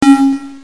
aidboom.mp3